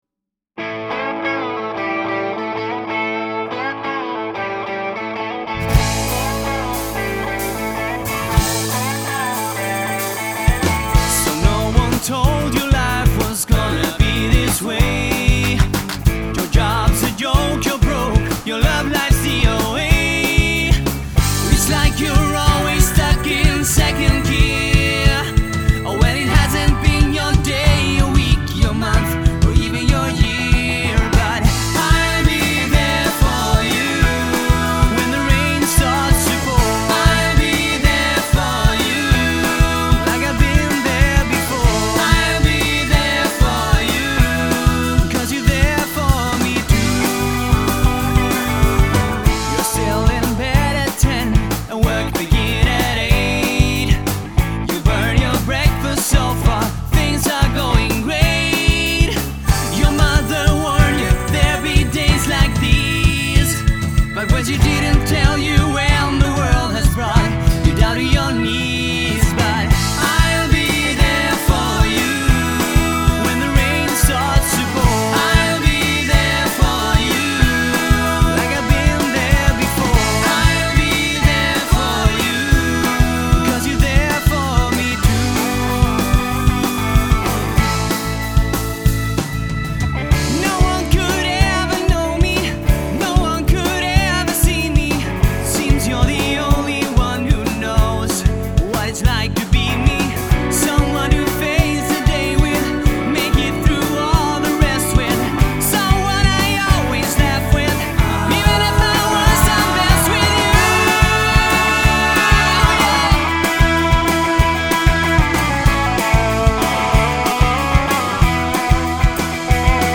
sång, piano.
gitarr.
bas.
trummor.